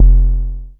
MM ANA D-TOM.wav